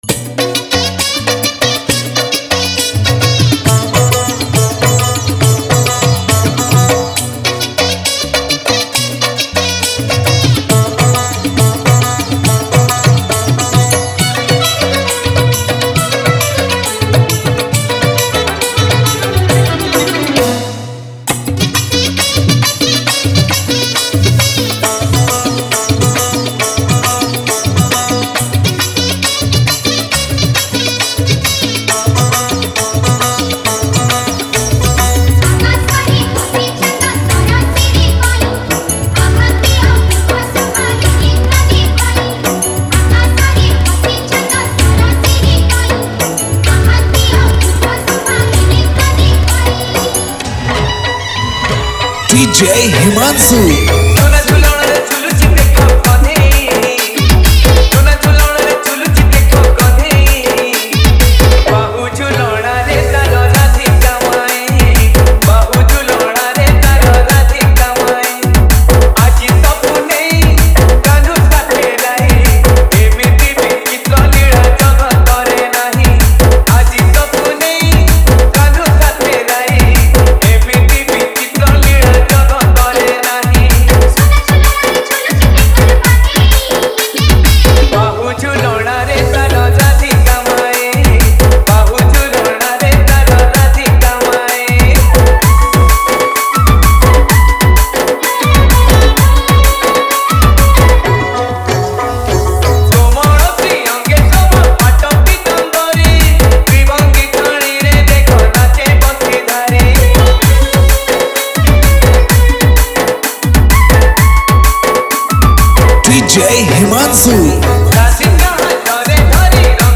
bhakti gana dj